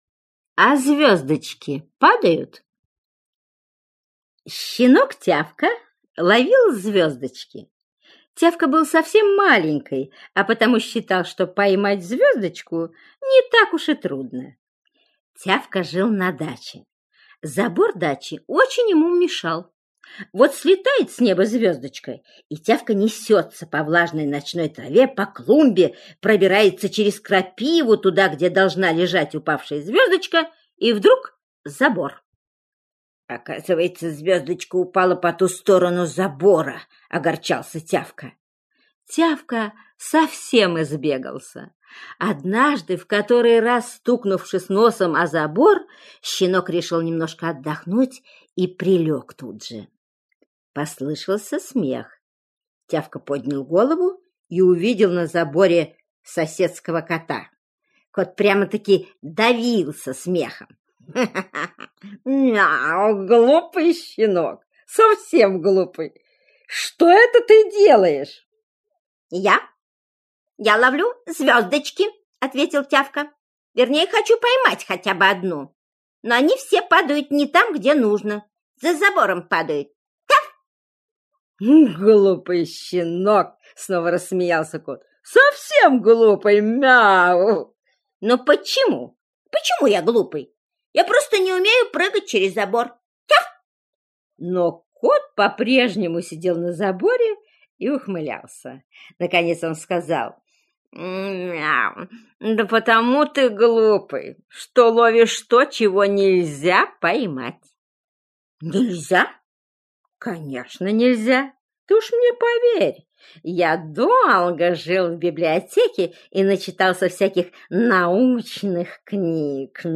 Аудиосказка «А звездочки падают»